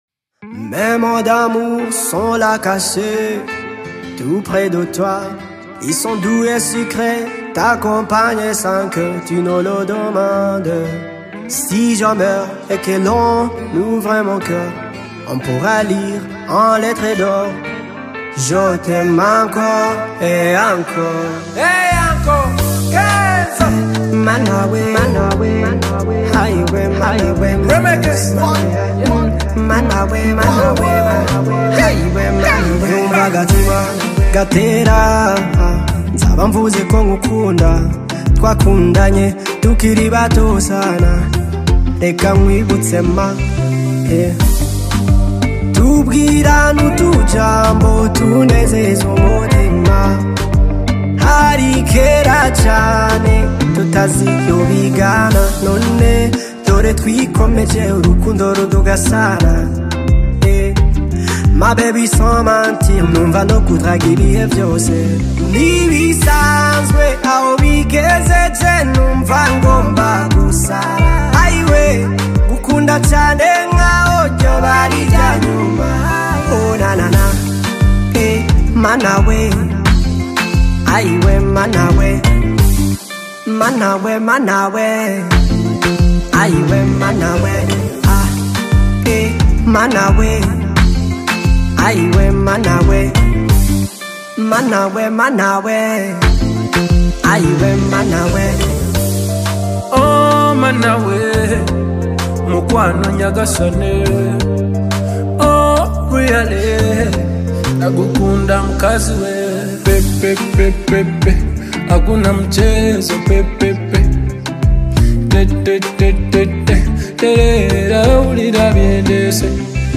uplifting lyrics, rich vocals, and danceable beats